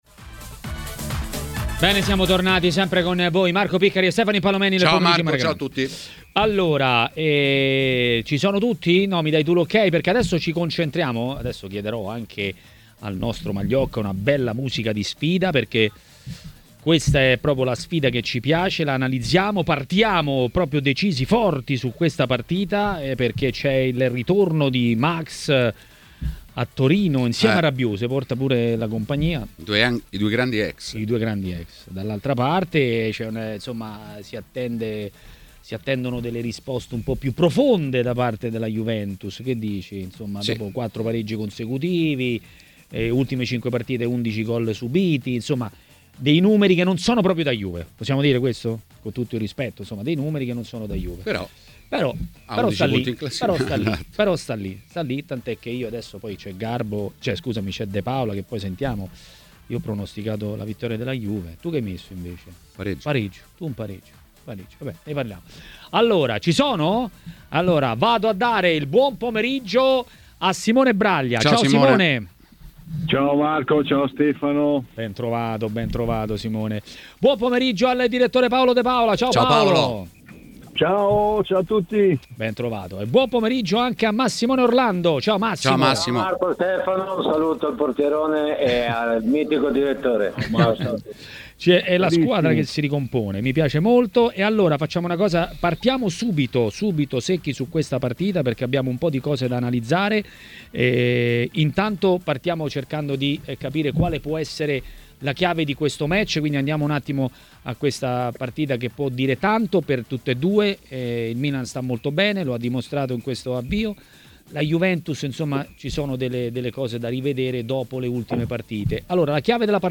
A Maracanà, nel pomeriggio di TMW Radio, tocca all'ex calciatore Massimo Orlando parlare delle notizie del giorno.